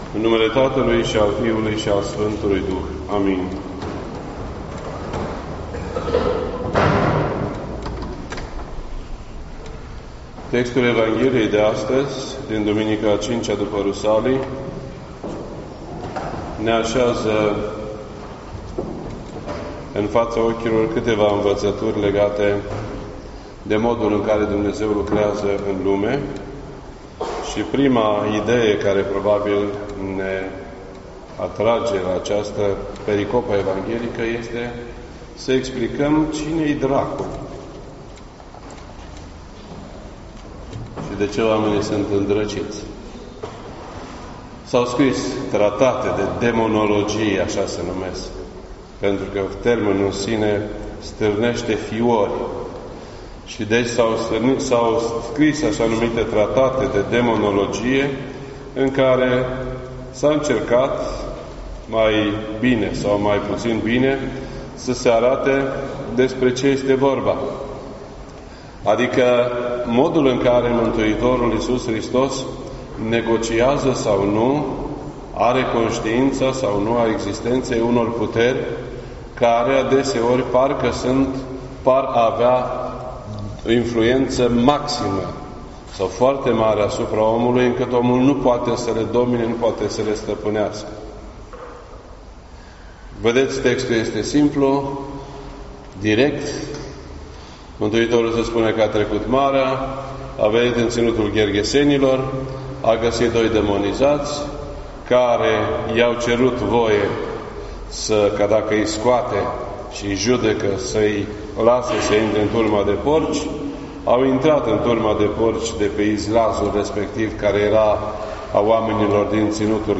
This entry was posted on Sunday, July 21st, 2019 at 7:51 PM and is filed under Predici ortodoxe in format audio.